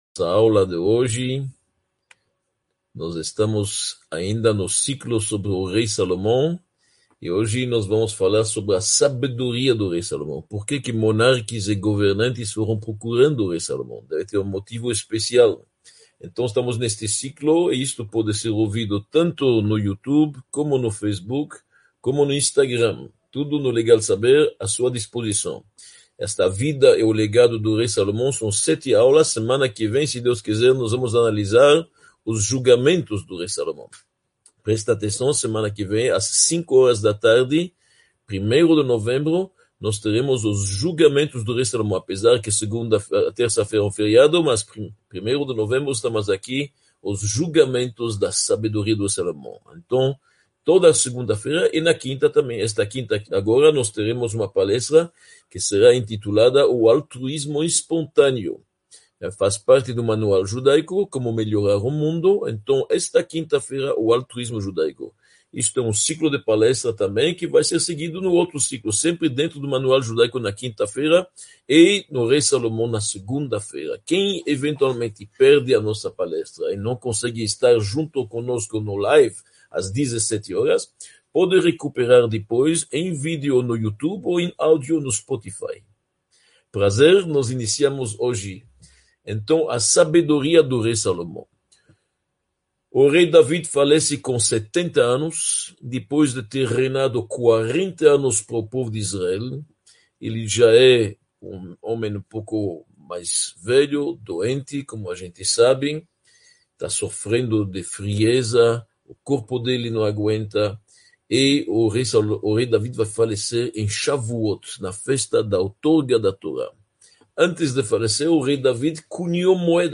02 – A Vida e o Legado do Rei Salomão | A Sabedoria do Rei Salomão | Aula 2